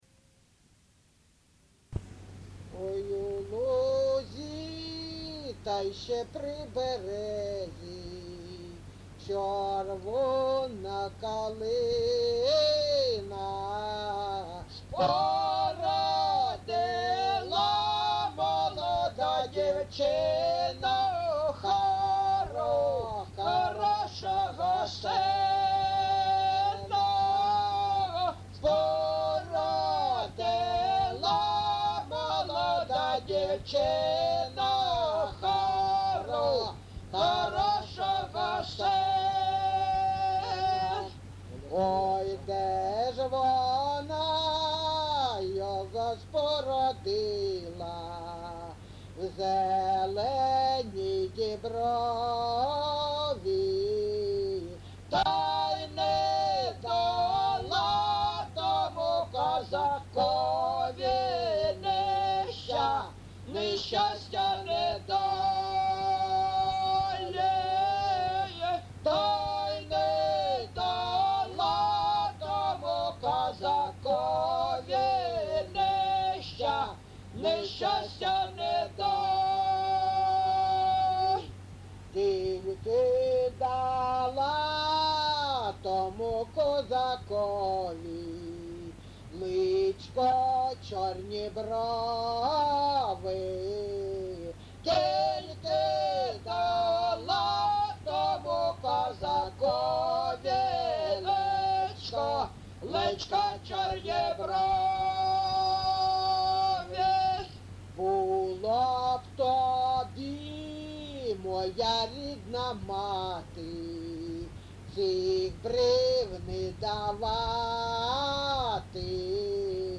ЖанрПісні з особистого та родинного життя, Солдатські
Місце записус. Григорівка, Артемівський (Бахмутський) район, Донецька обл., Україна, Слобожанщина